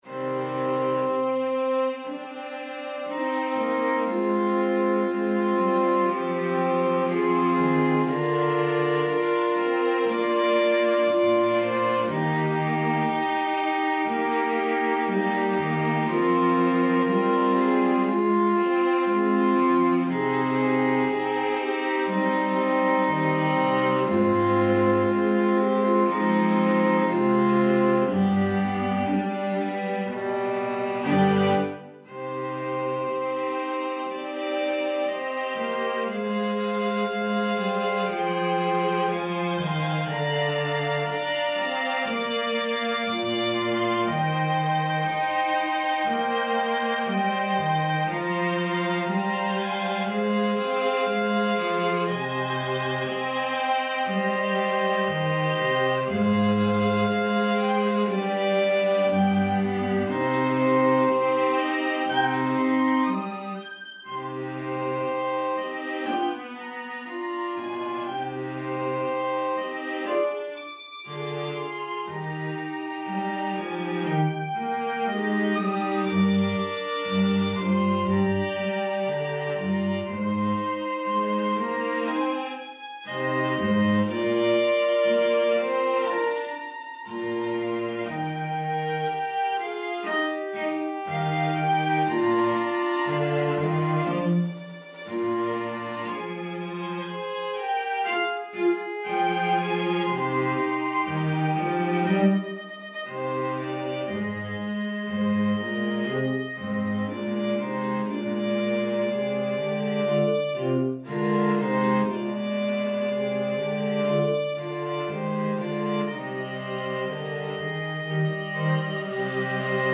Instrumental Version (音色: 弦楽四重奏)
アダージョ～アジタート、ハ短調、4分の4拍子。ソナタ形式。
String Quartet: 2 Violins, Viola and Cello (弦楽四重奏: ヴァイオリン2、ヴィオラ、チェロ)